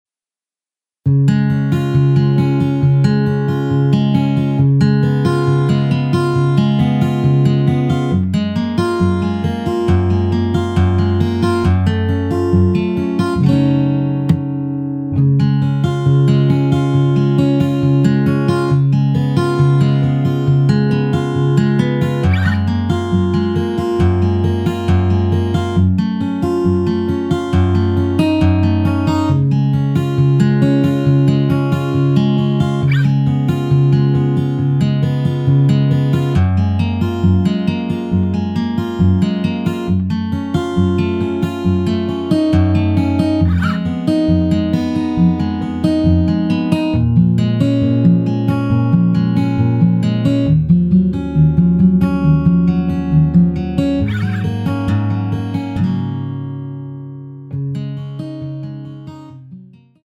엔딩이 페이드 아웃이라 라이브 하시기 좋게 엔딩을 만들어 놓았습니다.~
Db
앞부분30초, 뒷부분30초씩 편집해서 올려 드리고 있습니다.
곡명 옆 (-1)은 반음 내림, (+1)은 반음 올림 입니다.